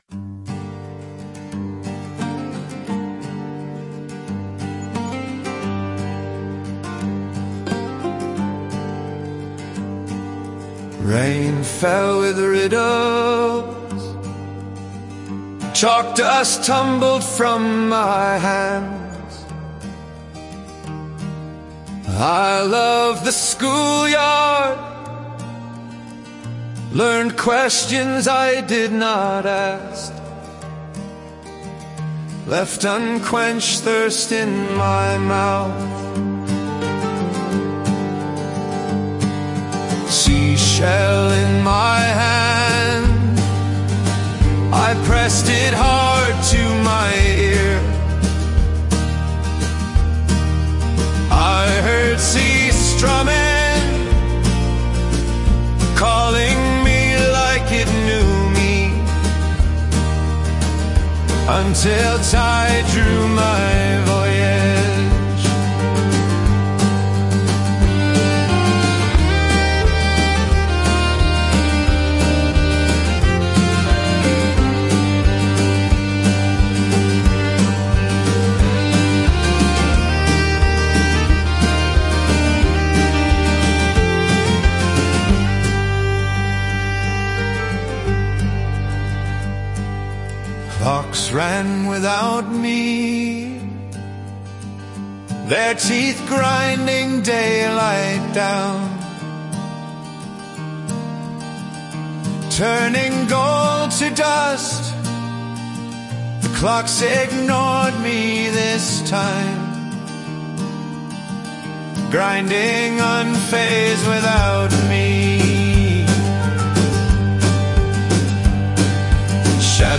Love the music and singers voice.